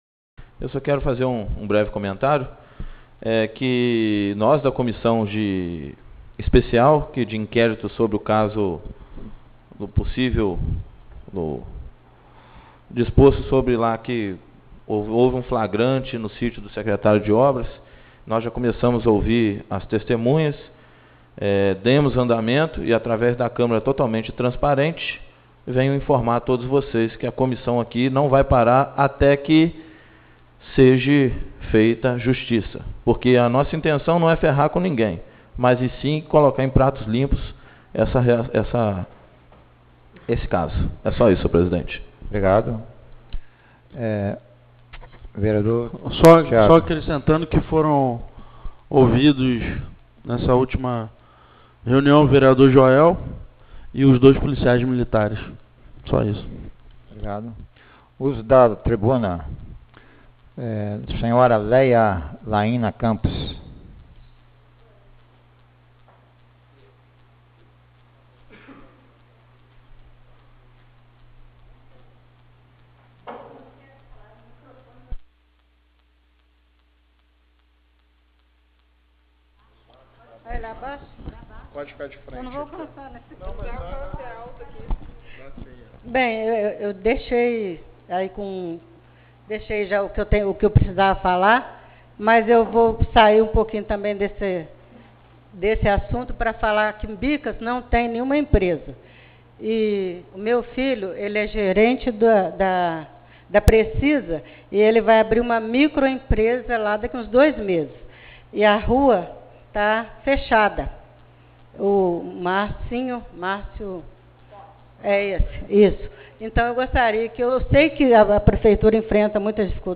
16ª Reunião Publica Ordinária